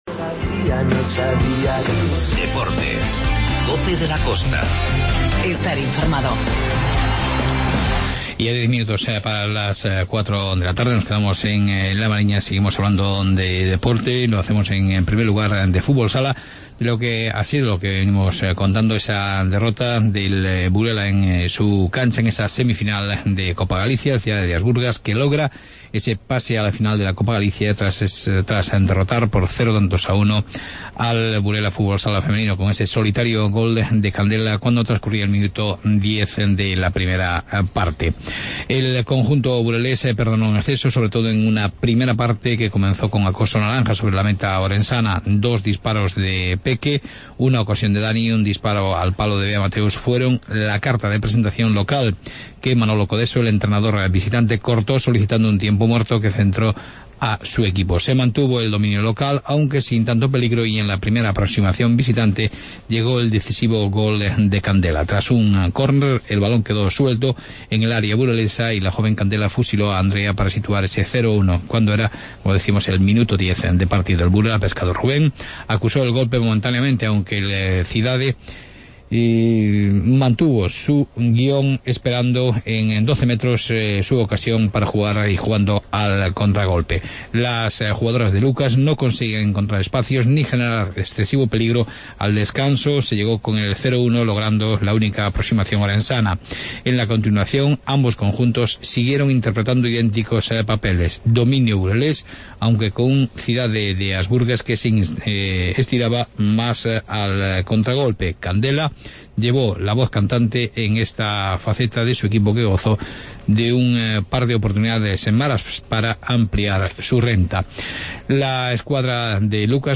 Informativo "Costa Viva"